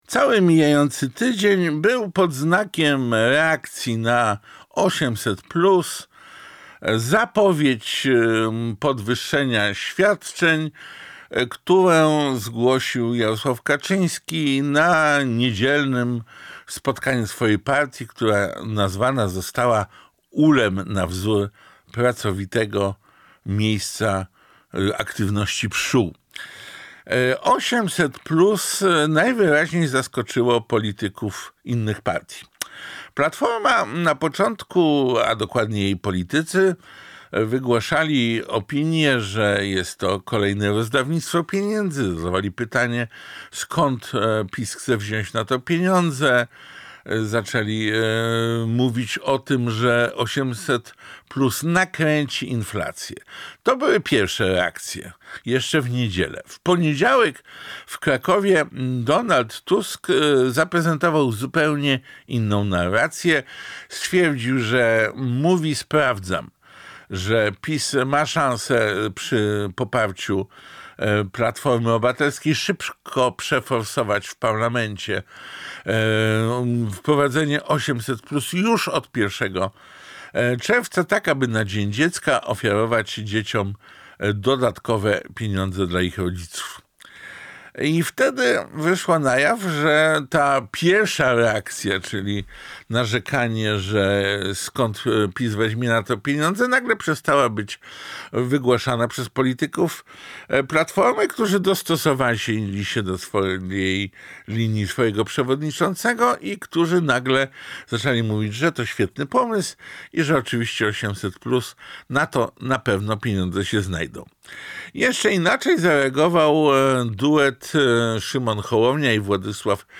Reakcje polityków na 800+ i wejście kampanii wyborczej w ostrą fazę. Felieton Piotra Semki